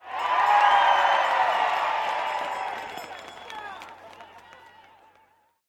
cheer.mp3